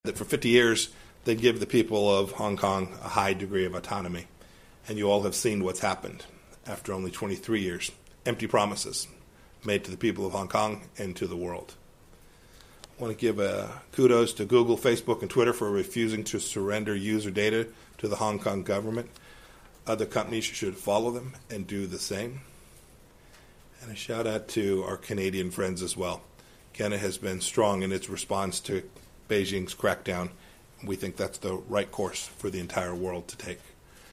美國國務卿蓬佩奧在美東時間星期三（7月8日）早上出席國務院記者會時評論有關中國的問題。他在提及有關香港情況時表示，北稱讚谷歌，臉書與推特拒絕向香港政府提交用戶的數據和網上資料，他認為其他公司應該跟隨有關的做法。